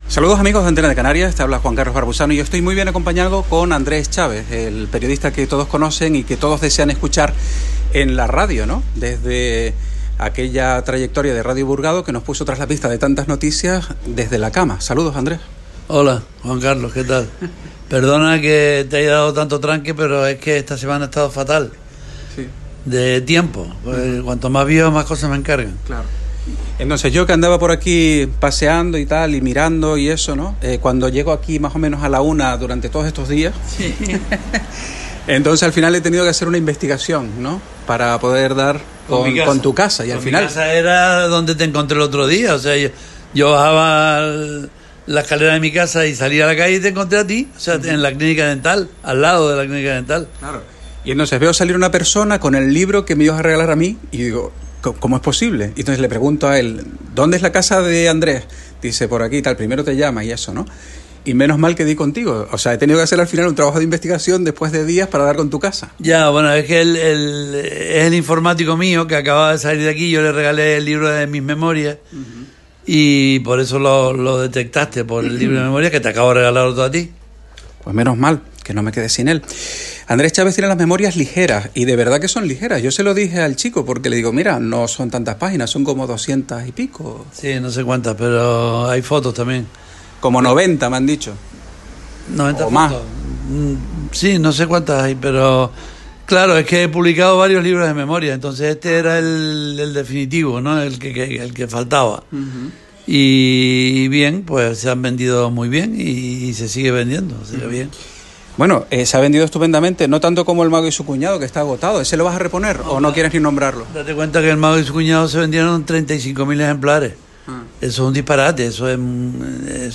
Fue improvisada, de verdad.